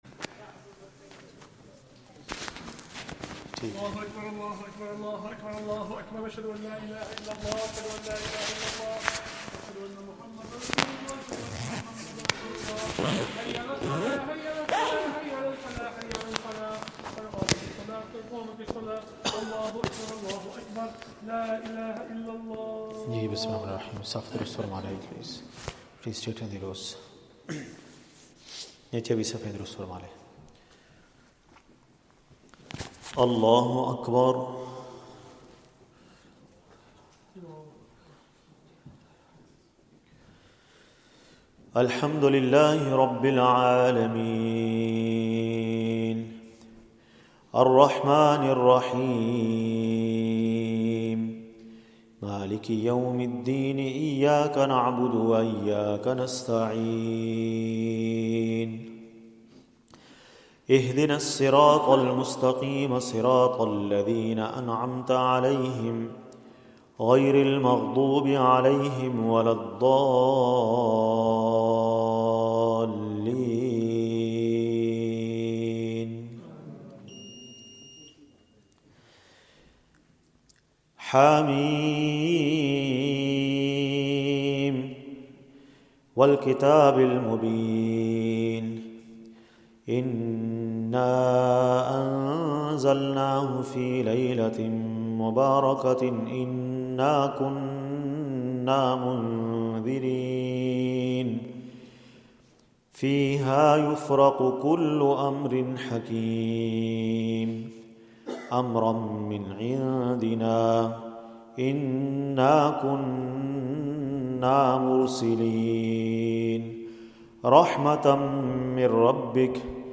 Taraweeh